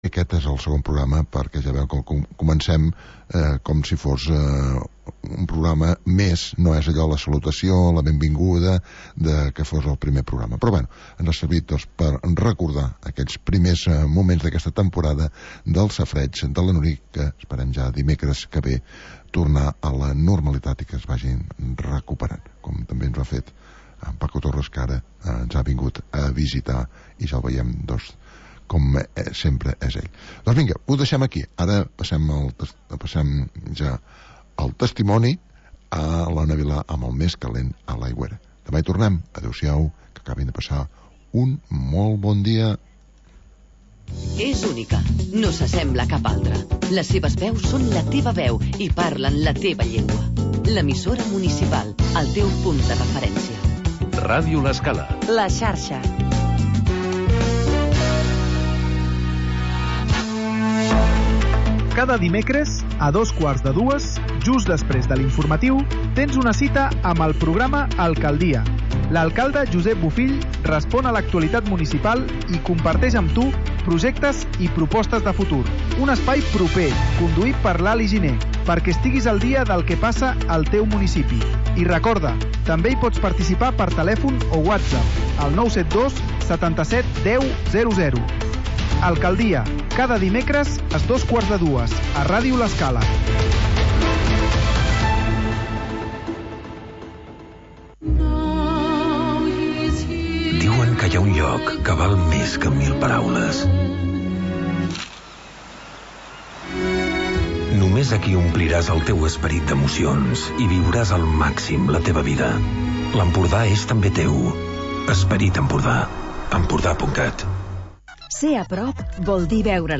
Magazin d'entretiment per acompanyar el migdiaompanyar